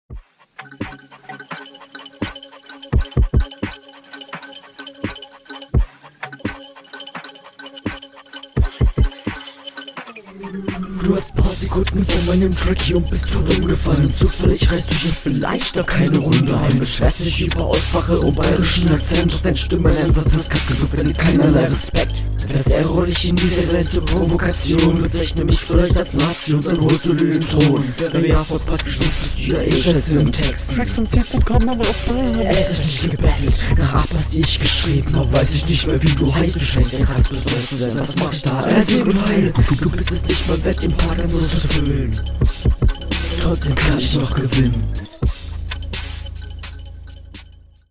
Mag solche Beats eigentlich sehr gerne, aber so ist das leider unhörbar.
Du rappst sehr leise wodurch manches verloren geht.